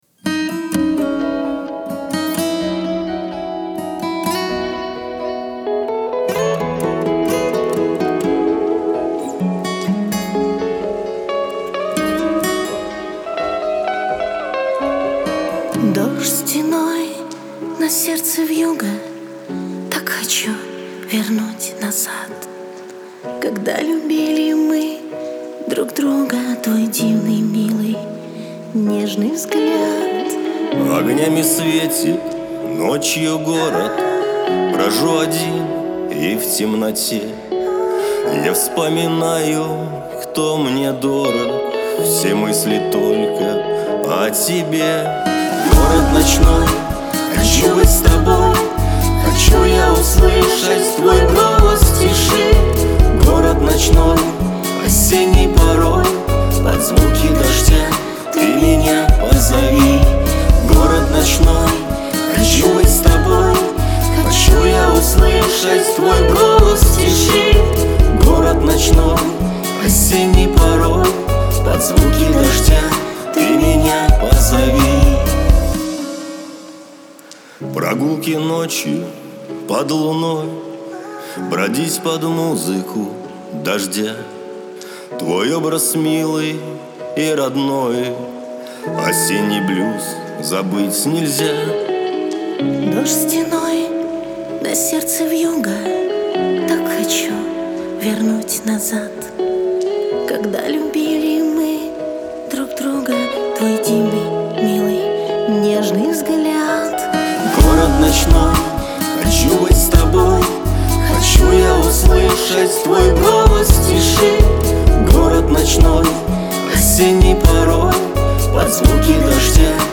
дуэт
Лирика